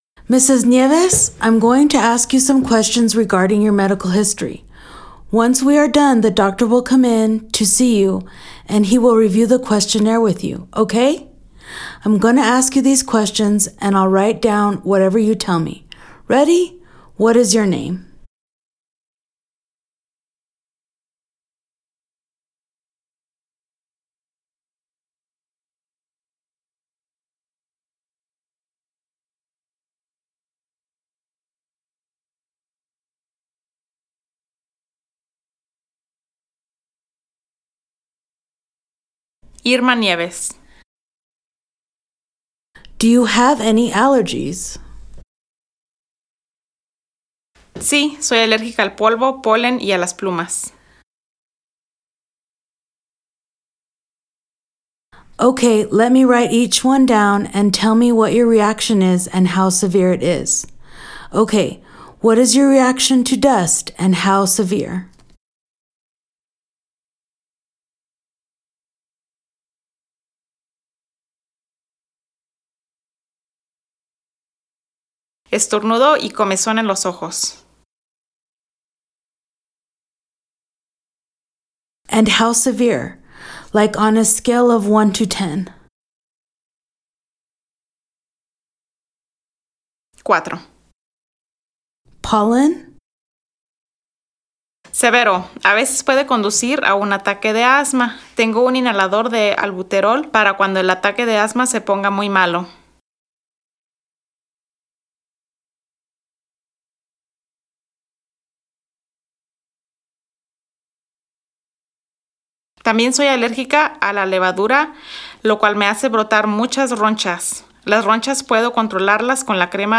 VCI-Practice-Dialogue-11-Patient-Medical-History-Intake-EN-SP.mp3